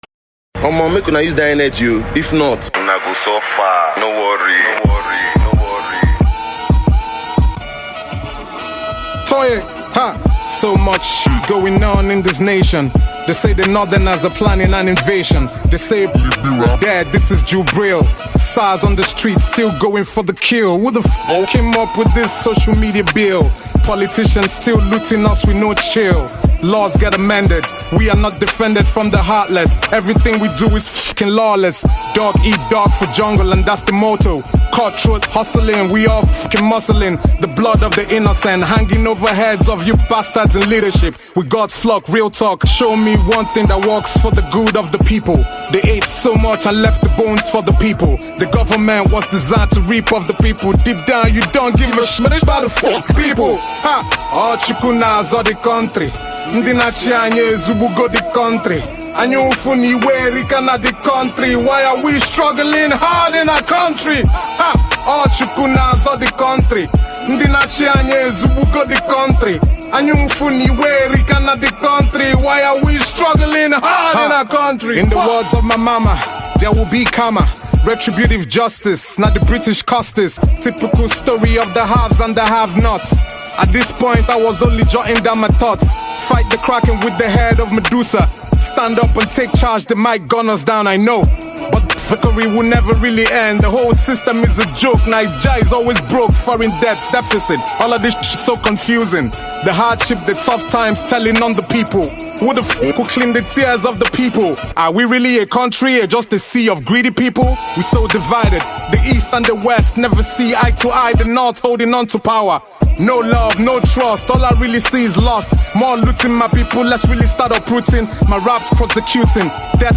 Nigerian talented rapper